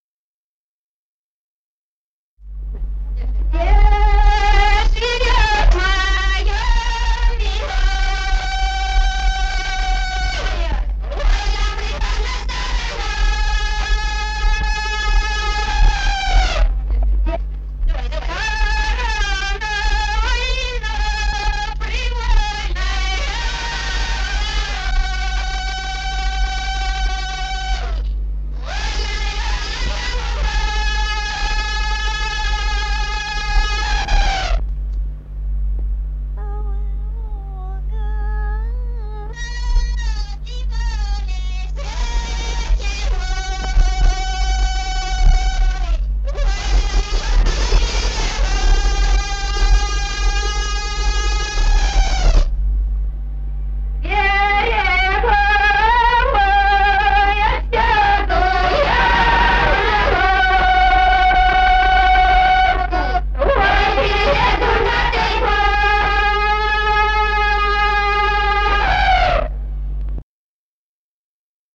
Народные песни Стародубского района «Где живёт моя милая», весняная девичья.
1954 г., с. Курковичи.